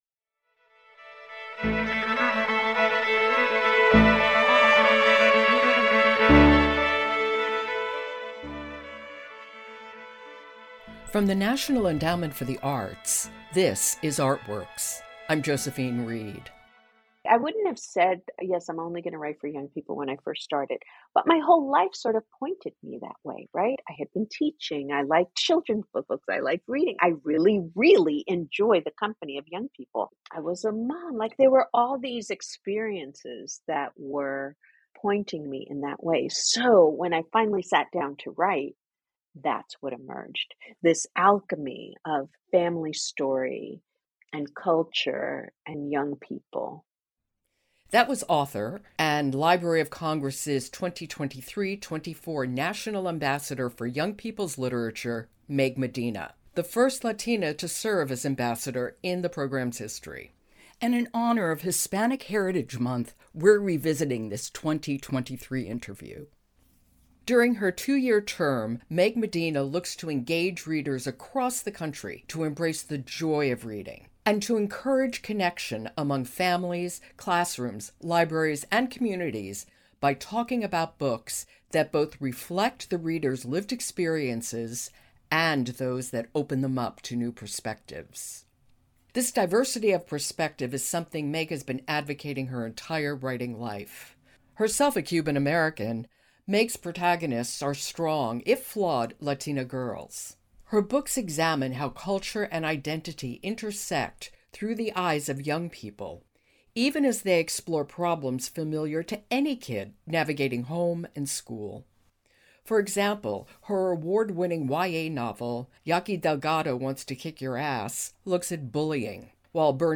In honor of Hispanic Heritage Month: Revisiting our 2023 interview with award-winning author Meg Medina, the first Latina National Ambassador for Young People’s Literature.